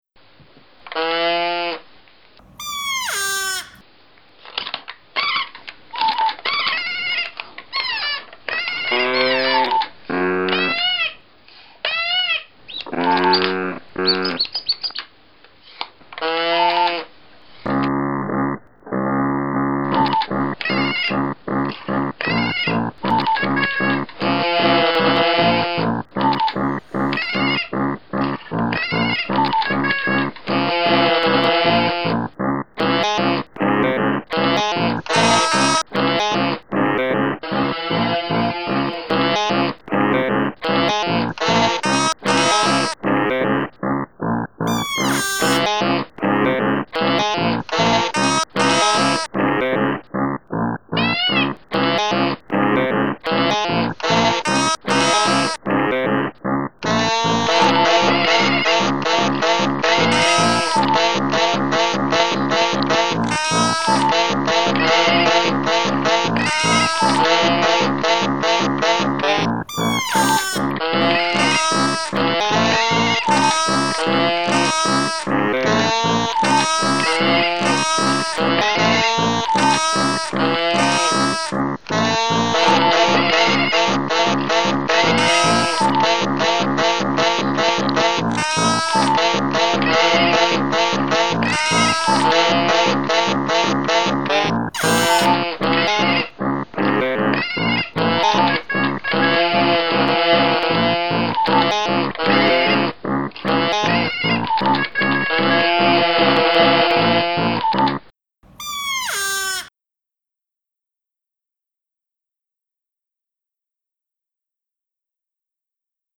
It's a Victorian novelty whose farmyard sounds are all made by pulling cords that operate a set of wooden bellows hidden inside.
Here's a tune I made by recording the sounds and playing around with them.